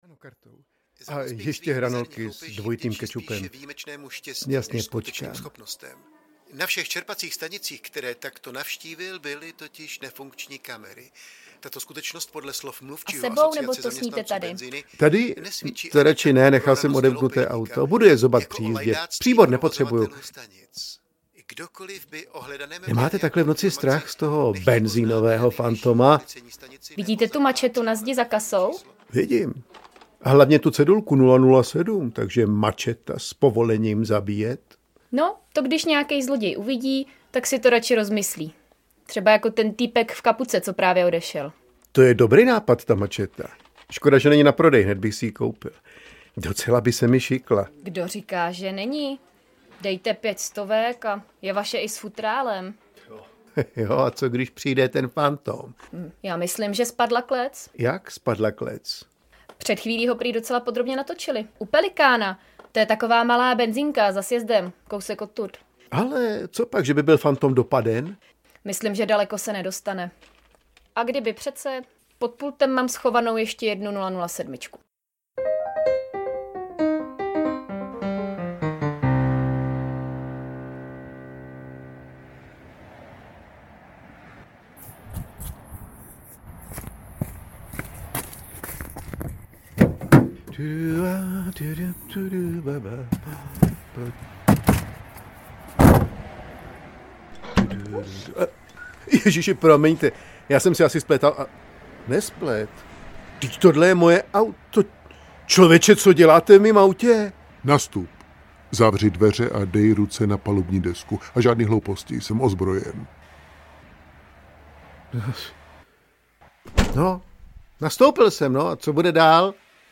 Ukázka z knihy
Klavírní doprovod